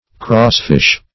crossfish - definition of crossfish - synonyms, pronunciation, spelling from Free Dictionary Search Result for " crossfish" : The Collaborative International Dictionary of English v.0.48: Crossfish \Cross"fish`\ (-f?sh`), n. (Zool.)